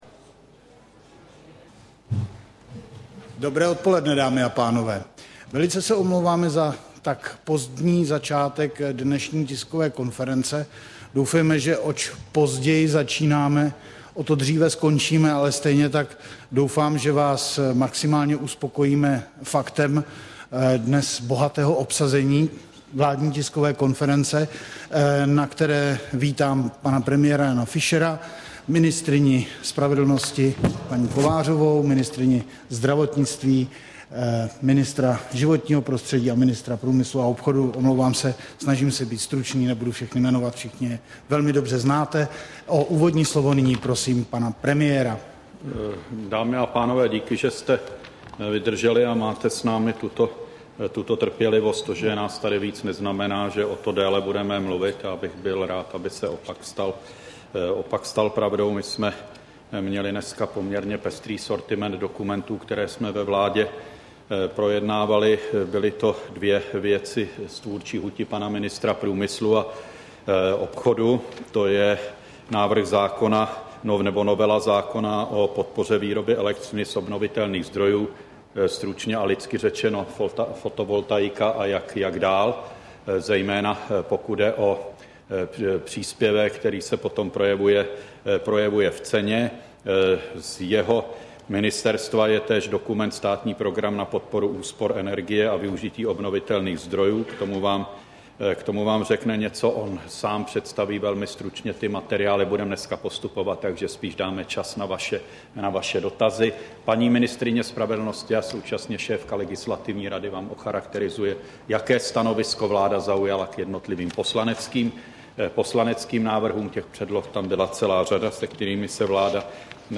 Tisková konference po zasedání vlády, 16. listopadu 2009